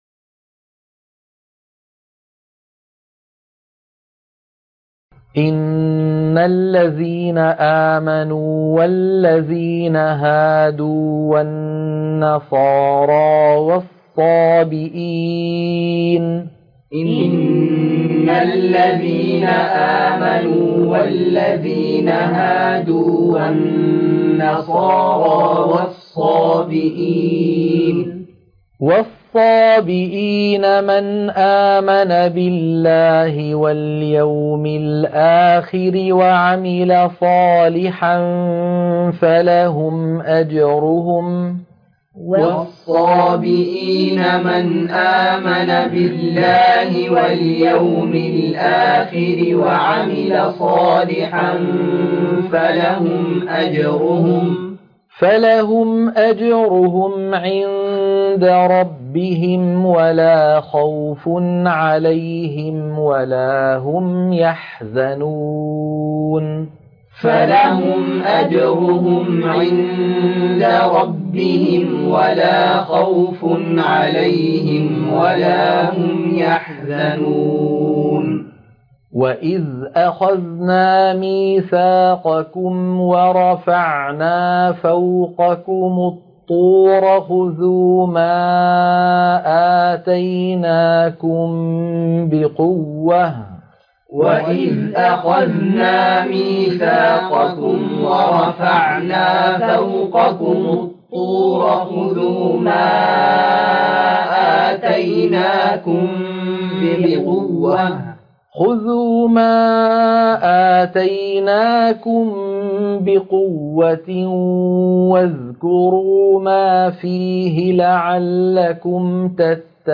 عنوان المادة تلقين سورة البقرة - الصفحة 10 _ التلاوة المنهجية